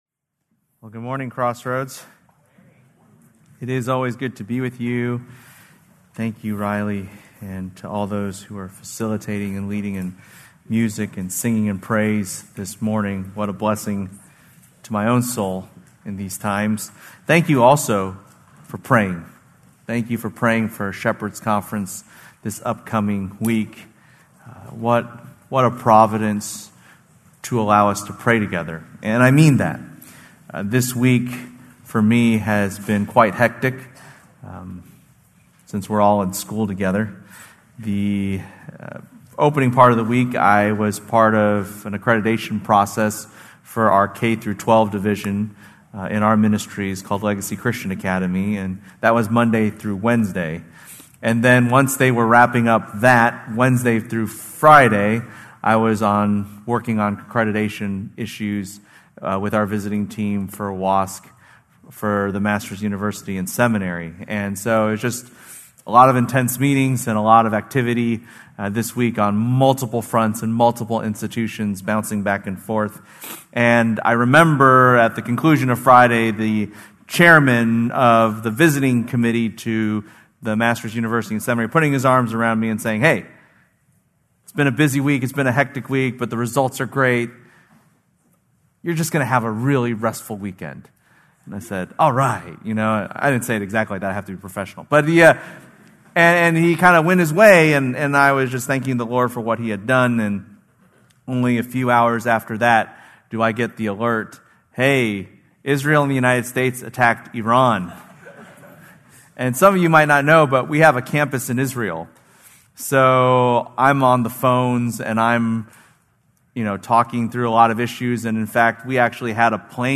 March 1, 2026 -Sermon | Crossroads | Grace Community Church